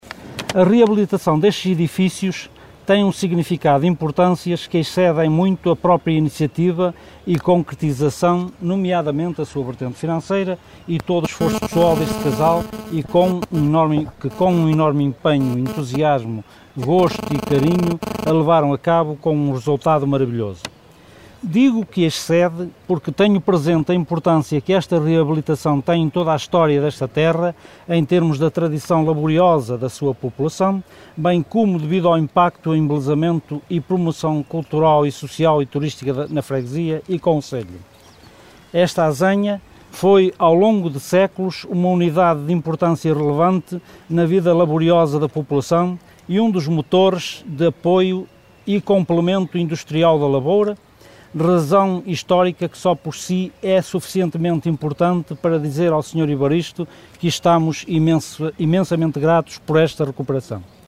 Presente na cerimónia de inauguração, o presidente da Junta de Freguesia de Vilar de Mouros, Carlos Alves, destacou a importância da reabilitação da azenha que “foi um dos motores de apoio e complemento industrial da lavoura”